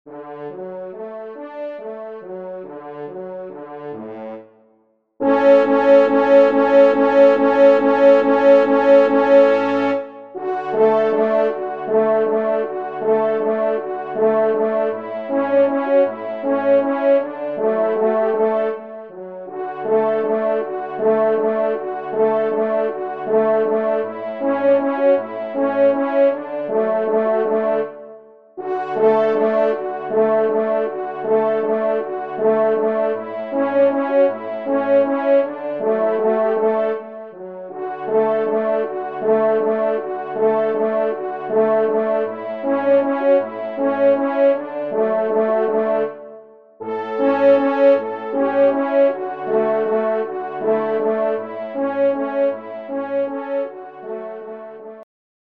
Valse
Genre :  Divertissement pour Trompes ou Cors en Ré (Valse)
3e Trompe